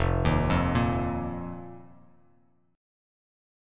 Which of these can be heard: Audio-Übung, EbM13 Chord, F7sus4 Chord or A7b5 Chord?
F7sus4 Chord